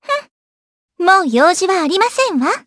Aisha-Vox_Skill3_jp.wav